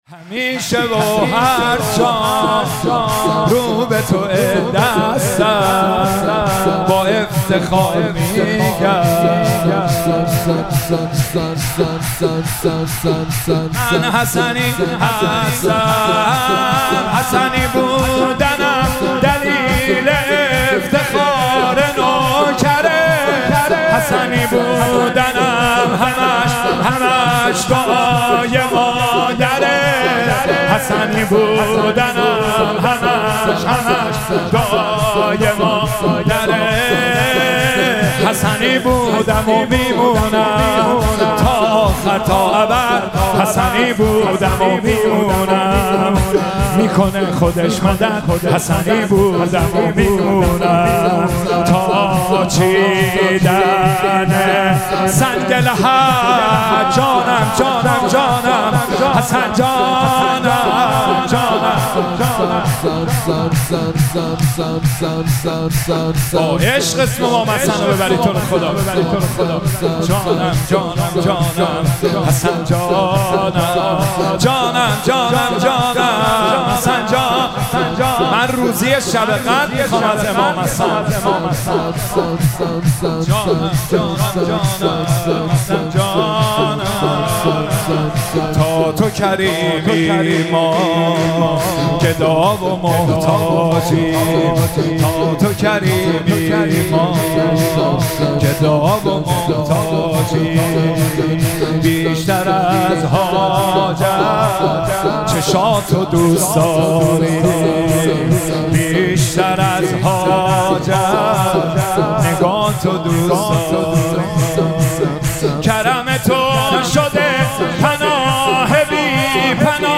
مراسم مناجات خوانی شب شانزدهم و جشن ولادت امام حسن مجتبی علیه السلام ماه رمضان 1444